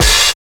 87 OP HAT.wav